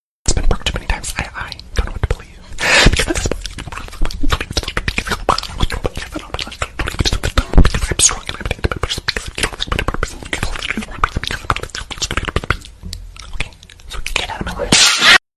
Reading A Book In The Library Efecto de Sonido Descargar